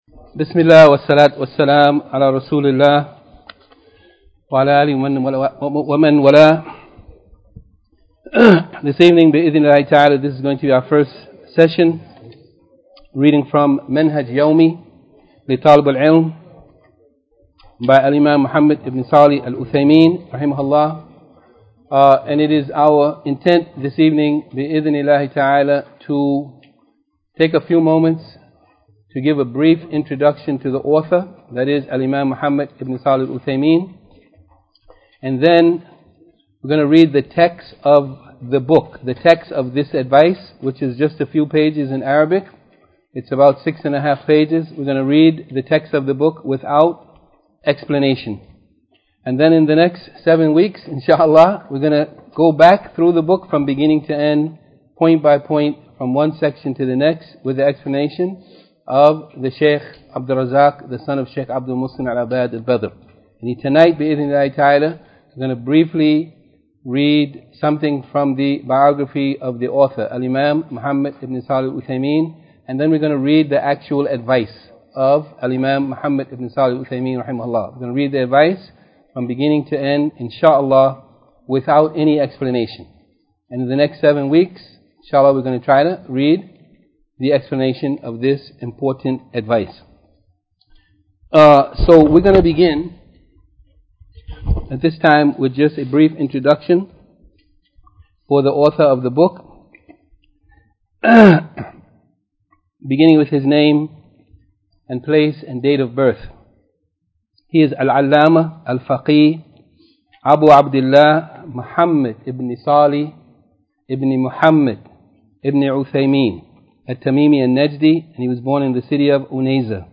Lecture No.4 Description: One's Manhaj (Daily Routine) With the Messenger of Allaah (SallAllaahu Alaihi wa Sallam): The First Advice: Giving Precedence To Loving him Over Every Created Being...